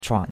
chuan3.mp3